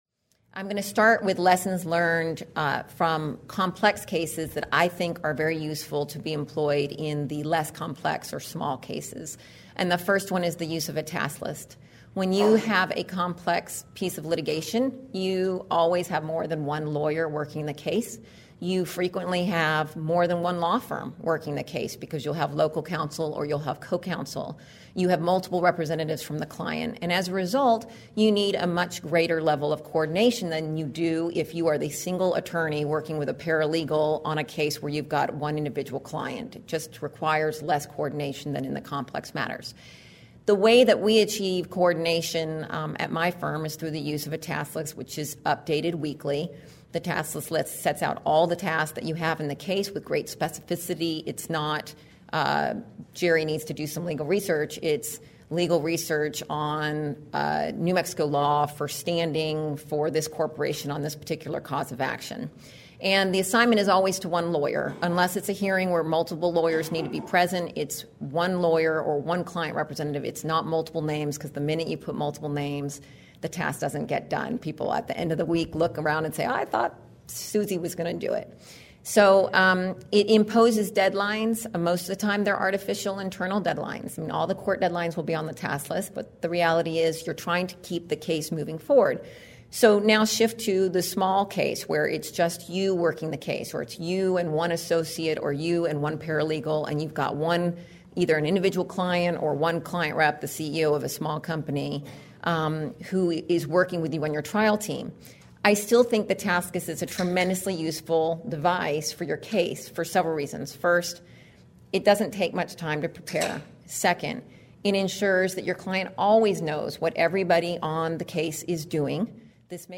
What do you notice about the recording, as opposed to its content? Originally presented: Oct 2015 Civil Litigation Conference